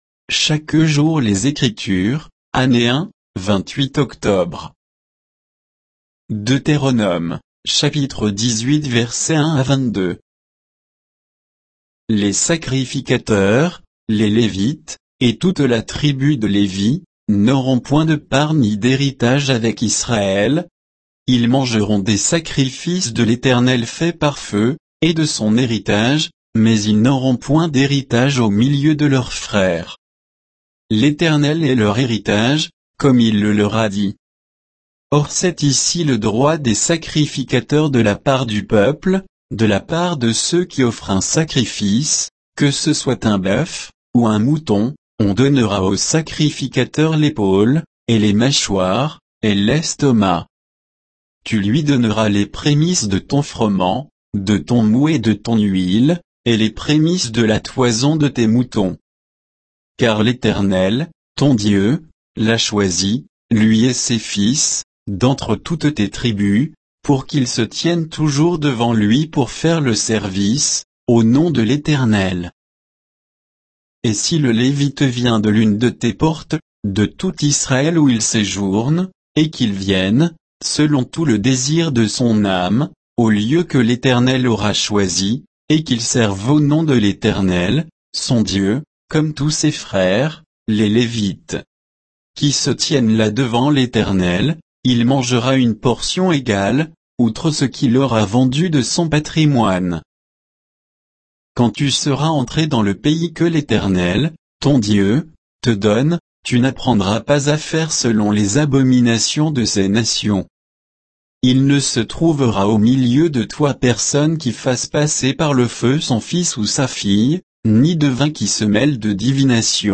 Méditation quoditienne de Chaque jour les Écritures sur Deutéronome 18